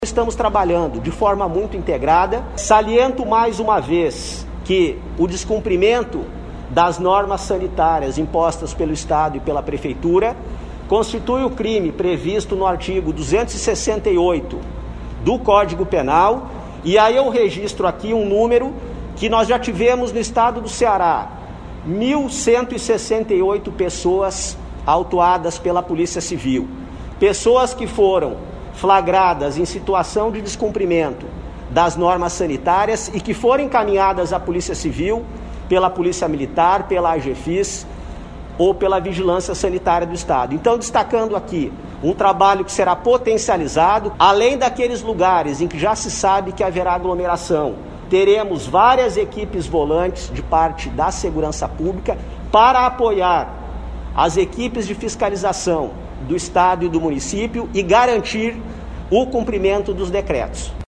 Em todos esses locais, haverá equipes de fiscalização e da Polícia Militar, garantindo o cumprimento das medidas sanitárias em razão de ser de extrema importância para o Estado”, ressaltou o secretário da Secretaria da Segurança Pública e Defesa Social (SSPDS), Sandro Caron, durante coletiva de imprensa.
O secretário Sandro Caron fala quais as consequências do eventual descumprimento às normas sanitárias estabelecidas.